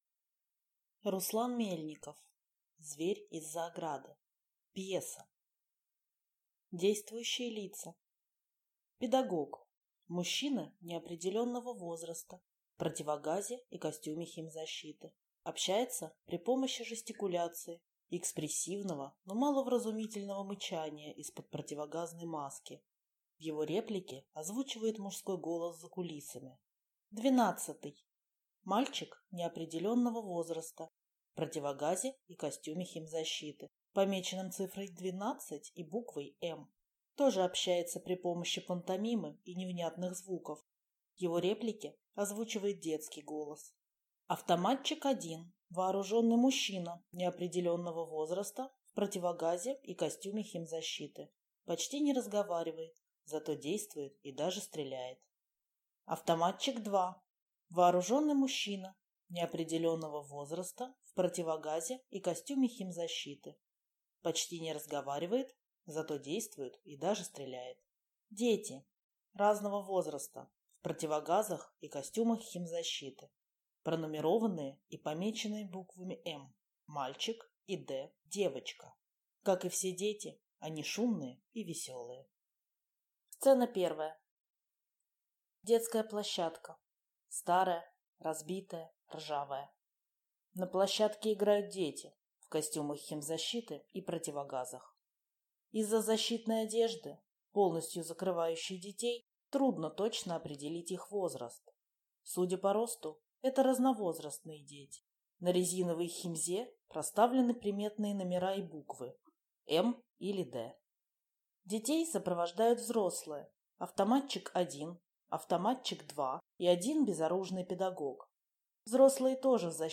Аудиокнига Зверь из-за ограды | Библиотека аудиокниг